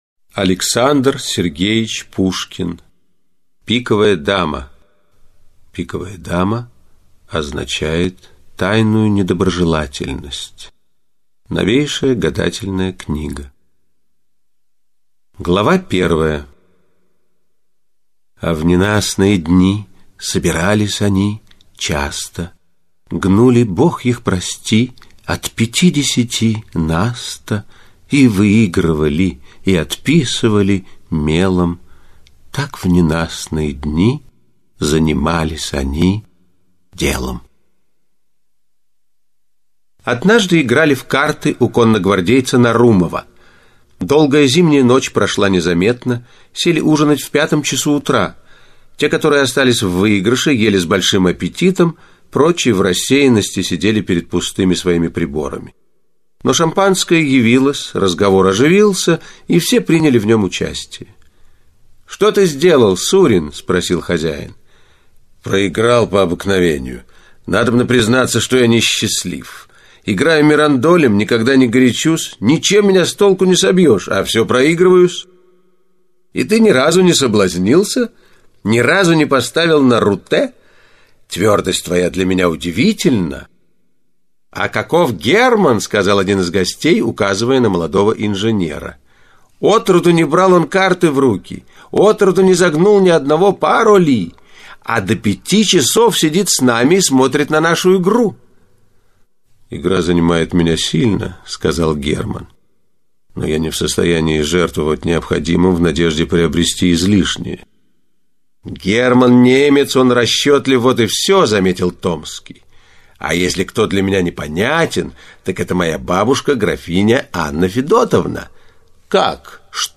Пиковая дама - аудио повесть Пушкина - слушать онлайн